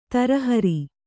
♪ tarahari